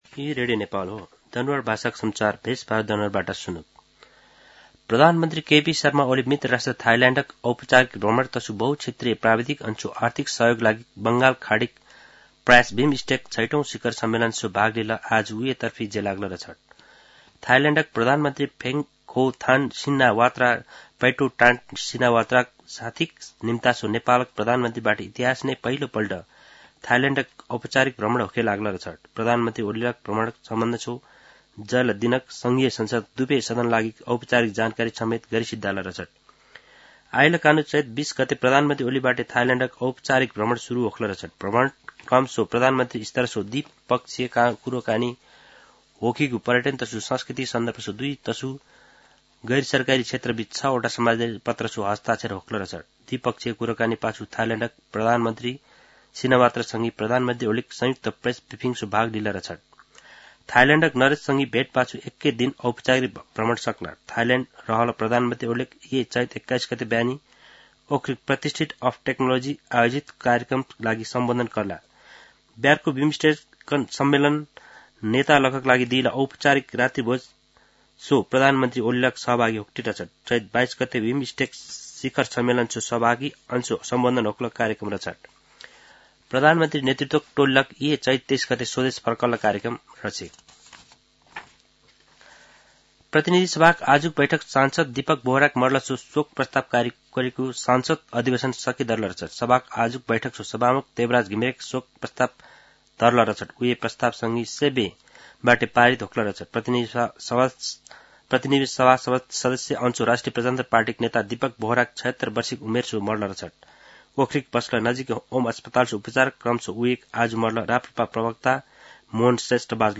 दनुवार भाषामा समाचार : १९ चैत , २०८१
danuwar-news.mp3